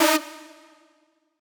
synth2_8.ogg